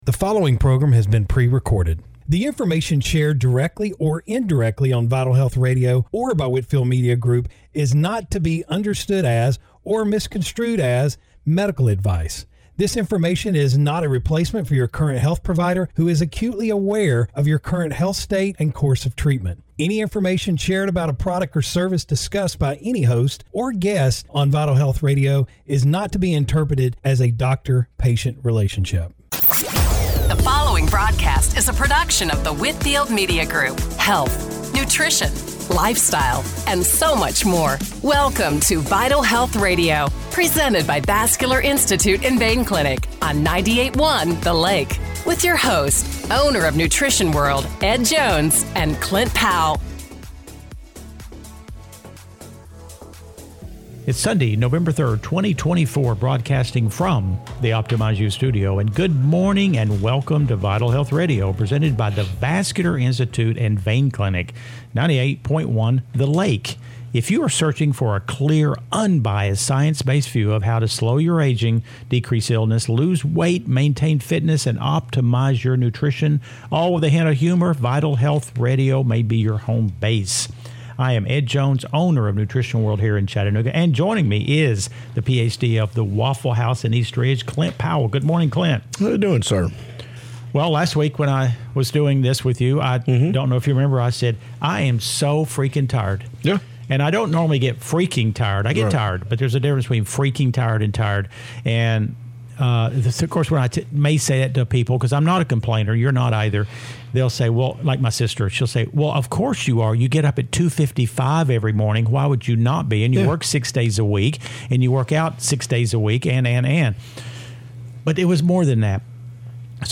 Radio Show – November 3, 2024 - Vital Health Radio